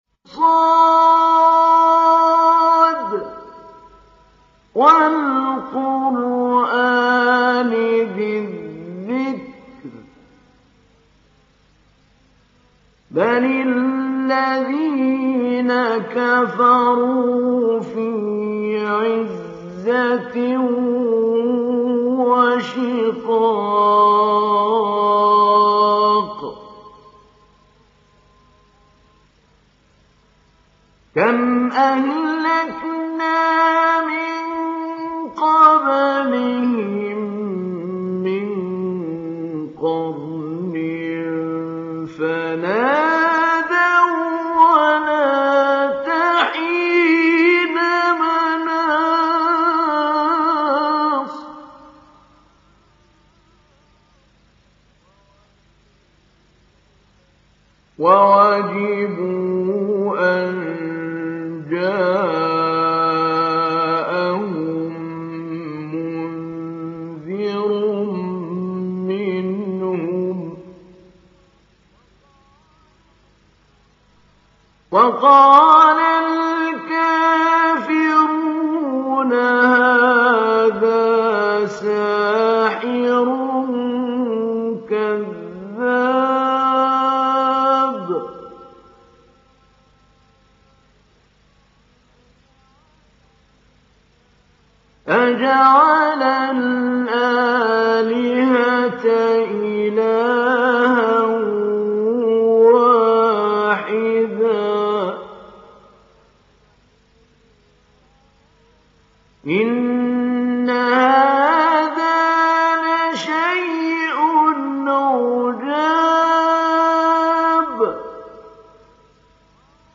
ডাউনলোড সূরা সদ Mahmoud Ali Albanna Mujawwad